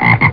frog.mp3